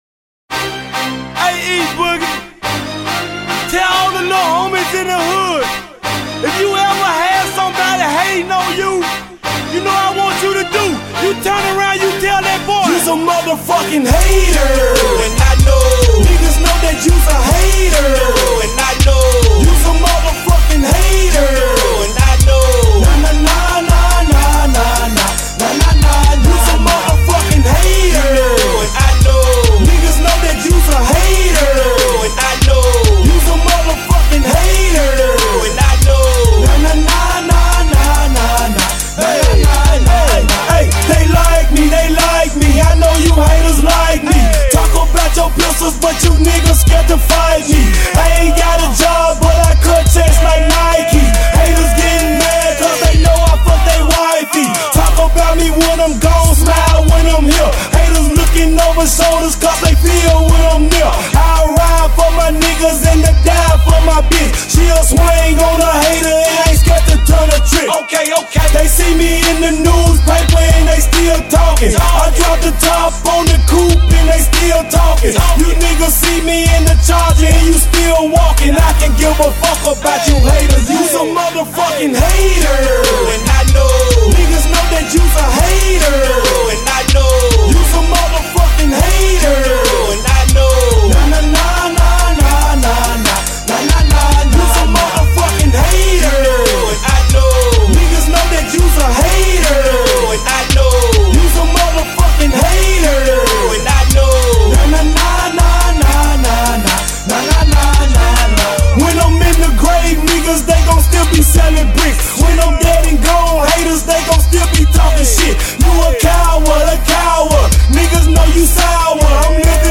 Genre: Memphis Rap.